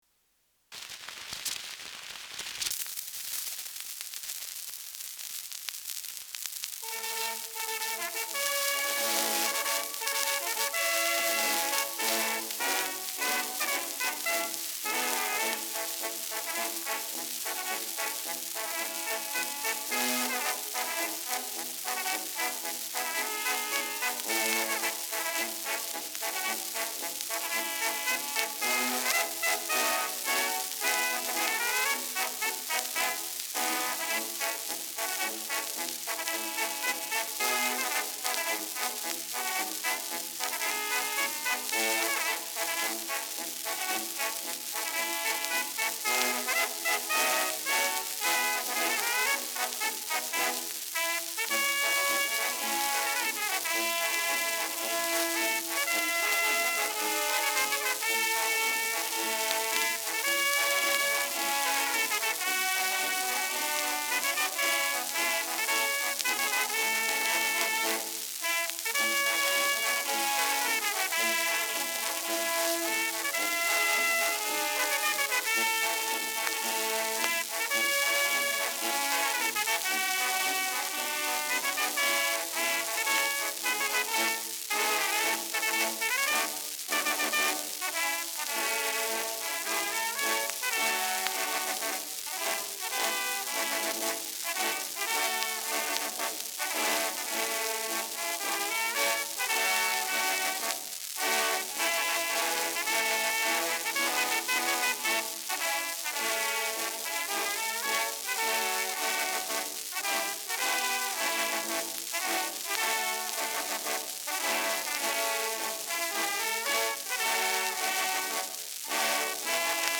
Schellackplatte
stärkeres Grundrauschen : dünner Klang
[Stuttgart] (Aufnahmeort)